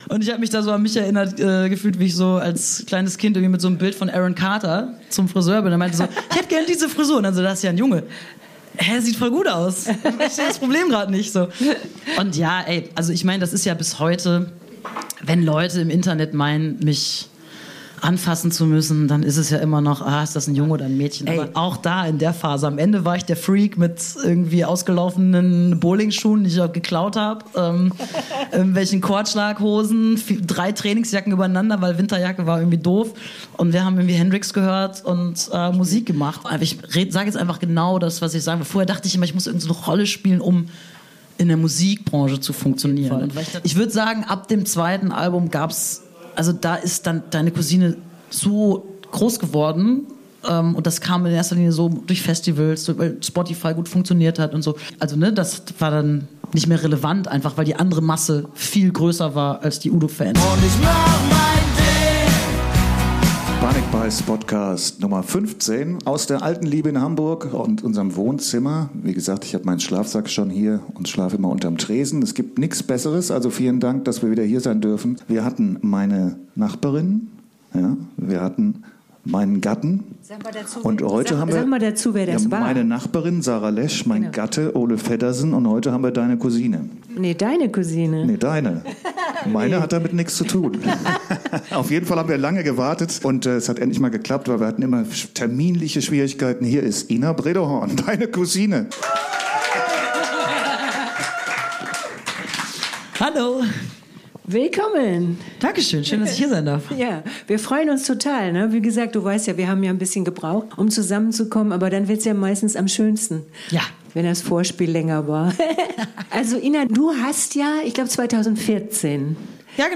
Live aus der Alten Liebe in St. Pauli.